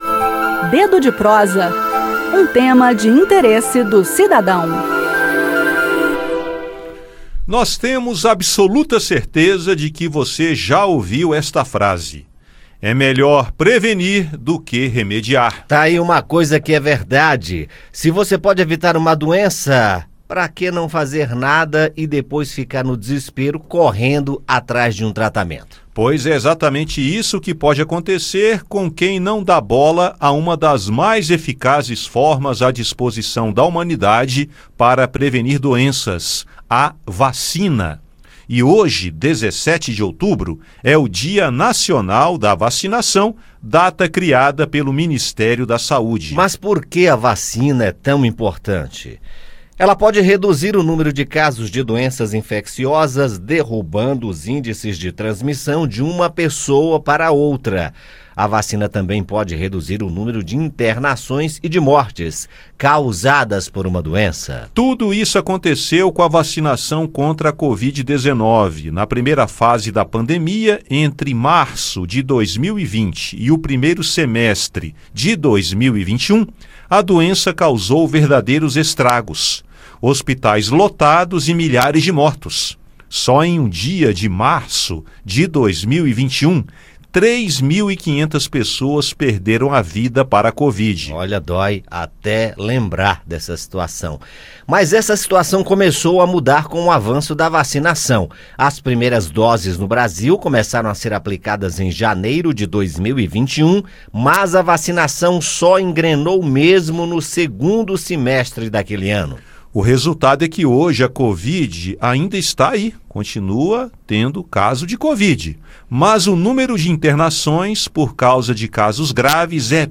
Neste 17 de outubro, Dia Nacional da Vacinação, o Ministério da Saúde lança mais uma campanha. Ouça no bate-papo a importância de manter as vacinas em dia e saiba como acessar a série especial que a Rádio Senado leva ao ar nesta semana: “Vacina: Ciência para a Vida”.